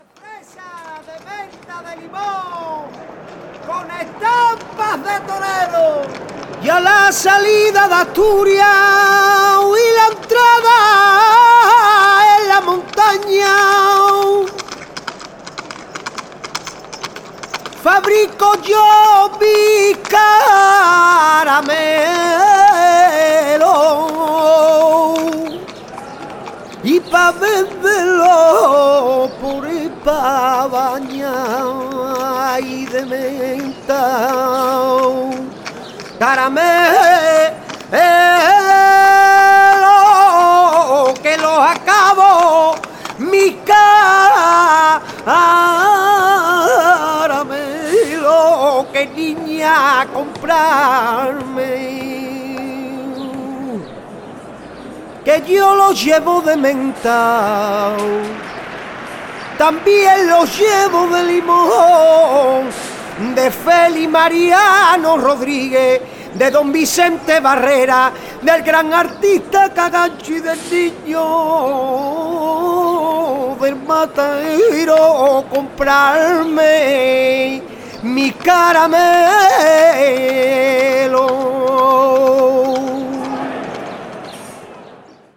II : CHANTS A CAPELLA
5) Pregones